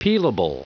Prononciation du mot peelable en anglais (fichier audio)
Prononciation du mot : peelable